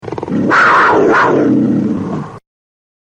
Jaguar Roar
Jaguar01.mp3